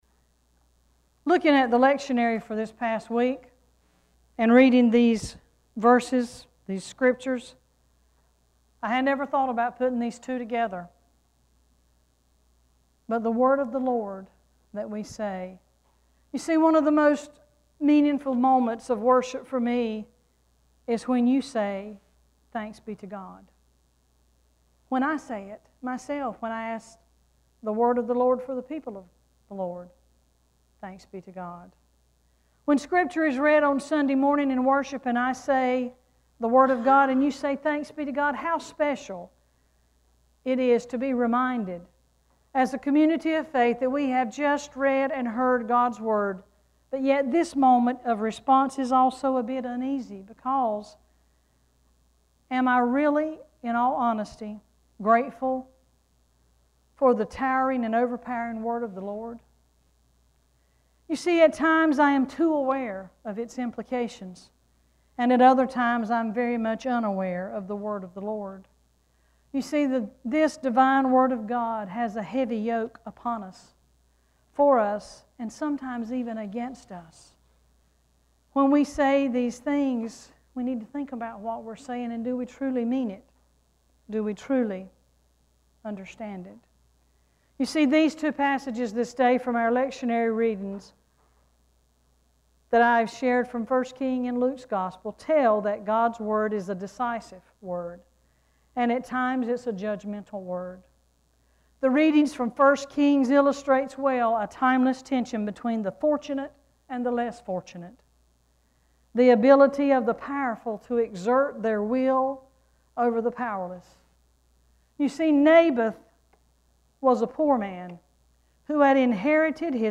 Worship Service 6-12-16:
6-12-sermon.mp3